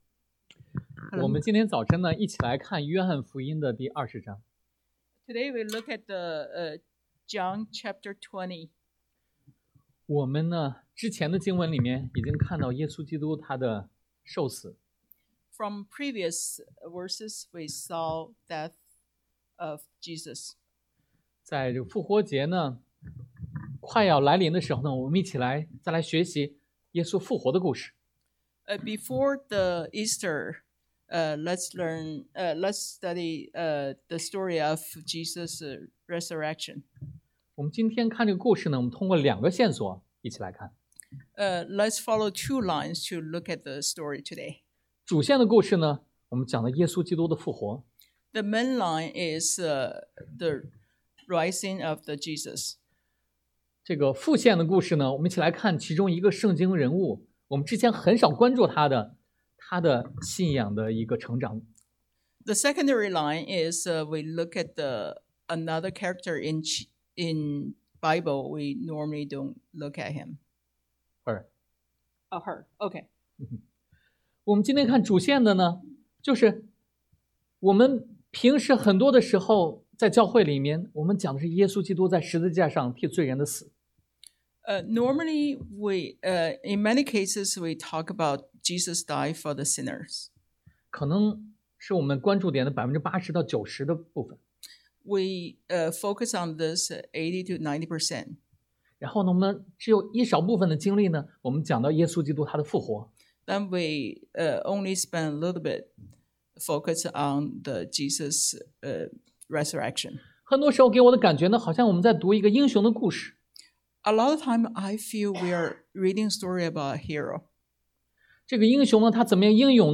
Passage: 约翰福音 John 20:1-18 Service Type: Sunday AM